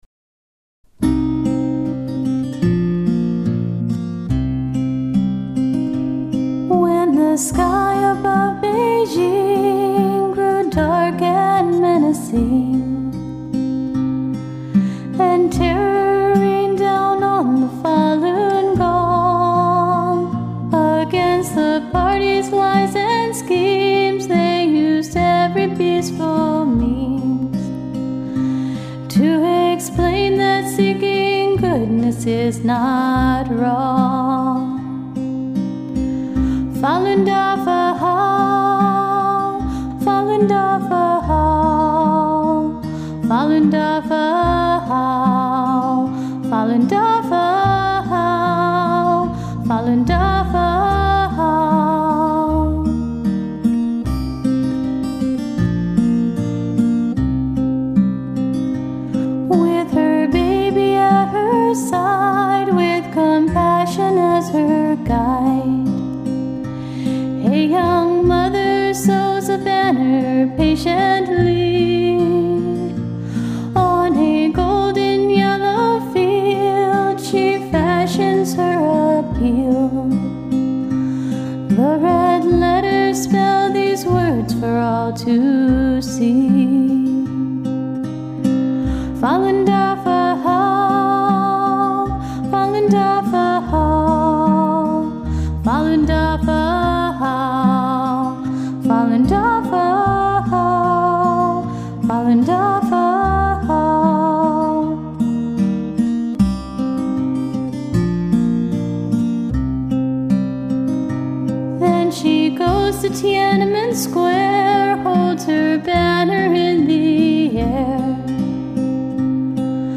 Vocals
Guitar
Acoustic Bass